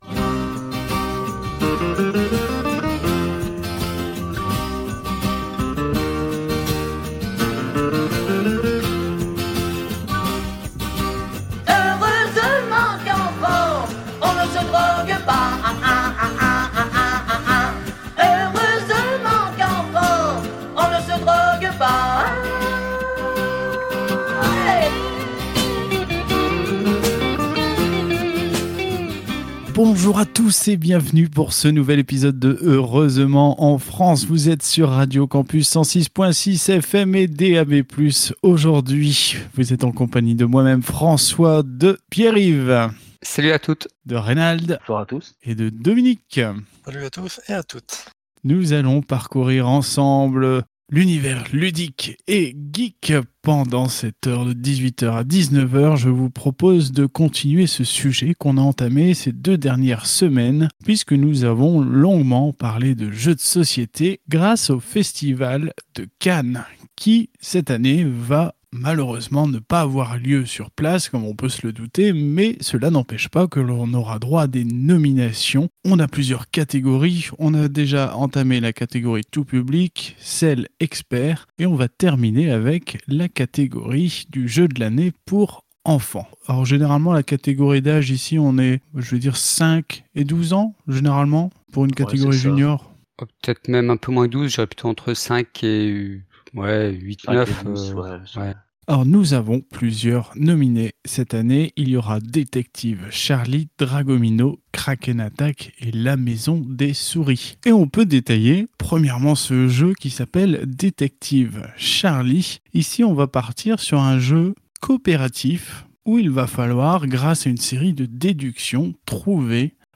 Au sommaire de cet épisode diffusé le 21 février 2021 sur Radio Campus 106.6 :